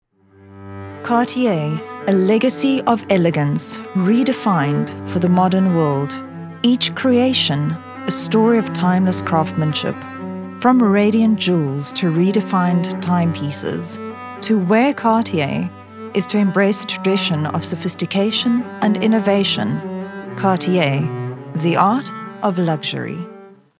authoritative, dramatic, elegant, soothing
Elegant, Sophisticated, and sometimes, just a little bit silly.
Cartier Soft Sell Voice Over